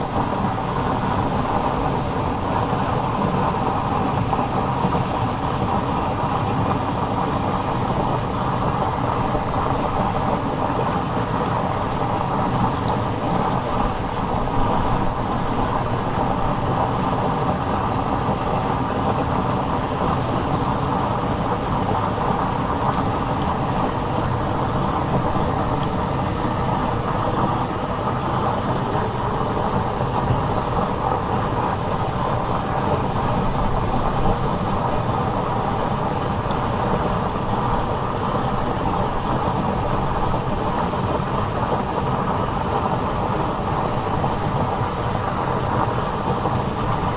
电器、技术和机电设备 " 热水池顶部水面
描述：录音是用Zoom H4n Handy Recorder录制的，它从约24dB归一化为0dB，但在水的泡沫中，噪音并不明显。
Tag: 场记录 进气 嘶嘶声 水疗 浴盆 空气 鼓泡